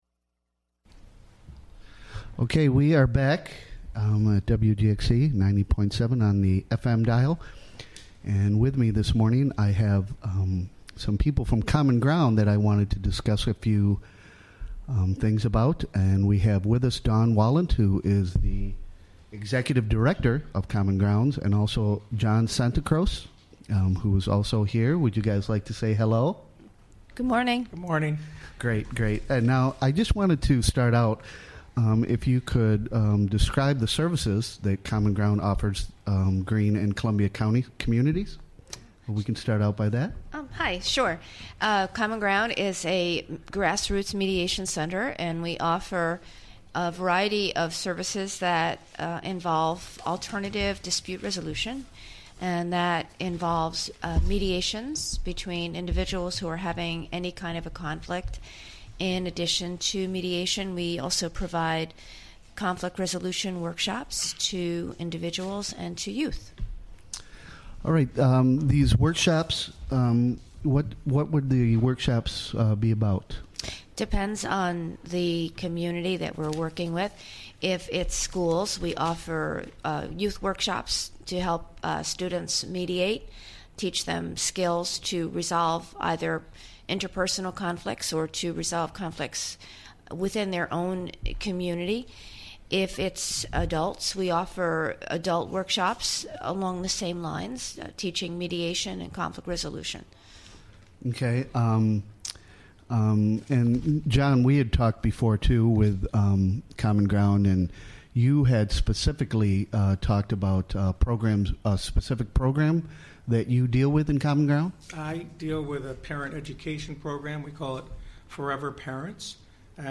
Recorded during the WGXC Morning Show, Tue., Aug. 23, 2016.